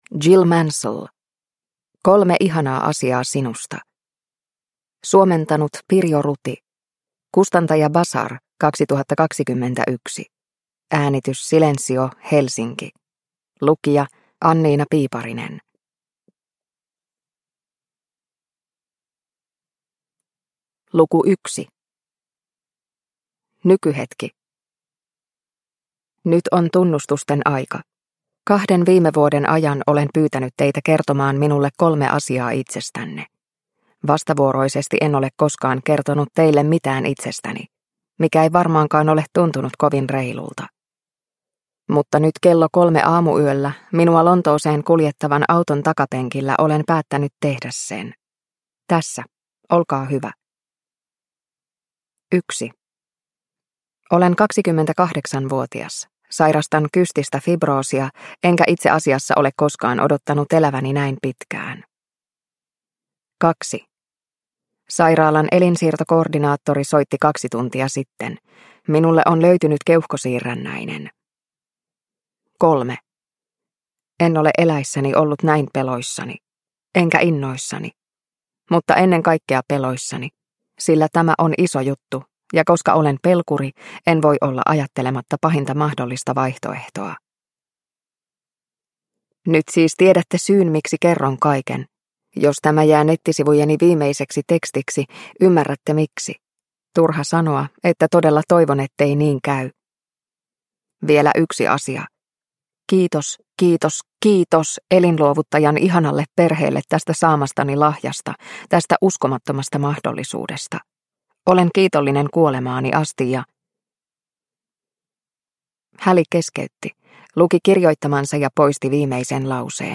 Kolme ihanaa asiaa sinusta – Ljudbok – Laddas ner